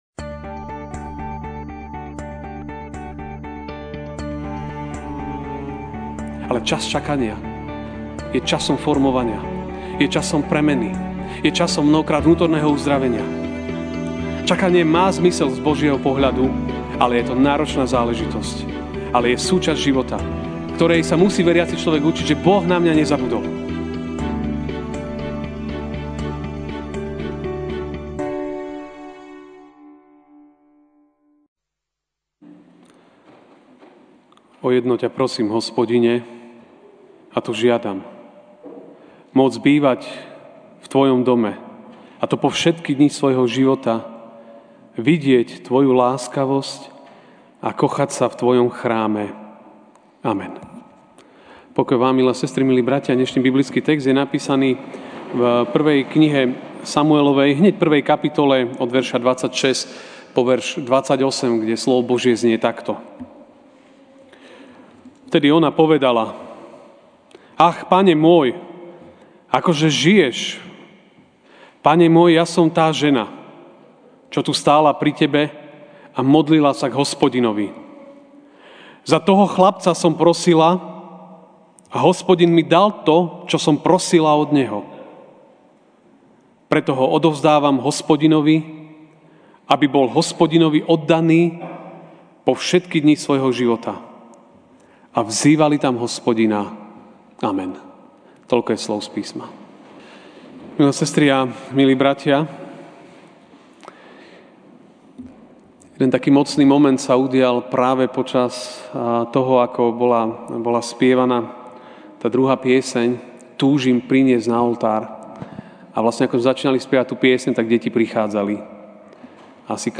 máj 12, 2019 Poslušná vďačnosť MP3 SUBSCRIBE on iTunes(Podcast) Notes Sermons in this Series Ranná kázeň: Poslušná vďačnosť (1S 2, 1-3) Vtedy ona povedala: Ach, pane môj!